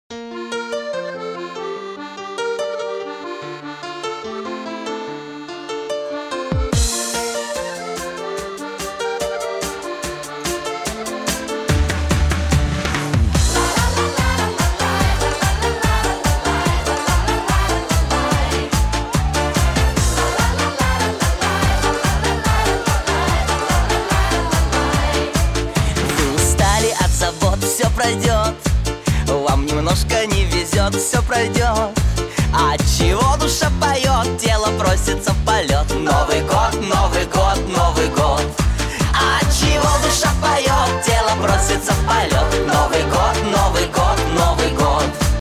Жанр: Эстрада